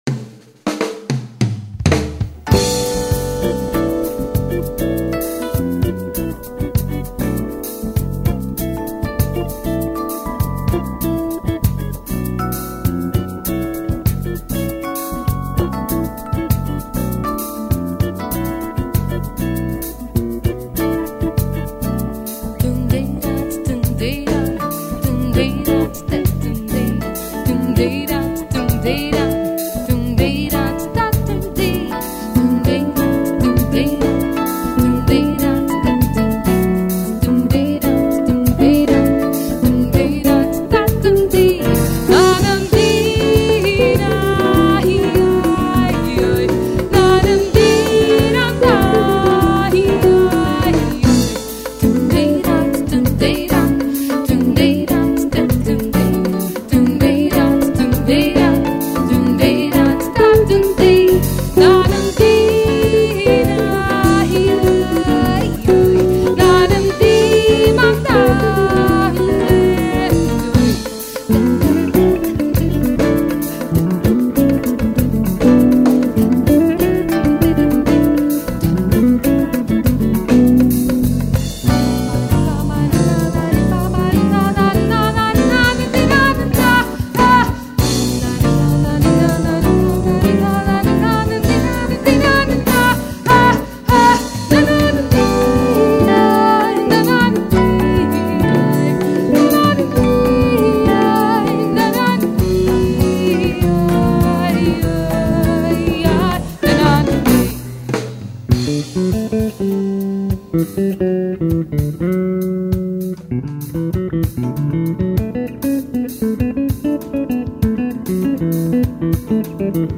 417   04:37:00   Faixa:     Instrumental
Piano Acústico
Violao Acústico 6
Bateria
Baixo Elétrico 6
Percussão